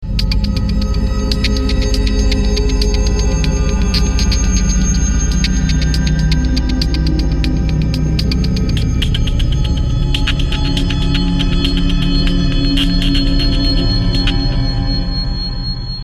描述：环境紧张的工人
Tag: 120 bpm Ambient Loops Fx Loops 2.69 MB wav Key : Unknown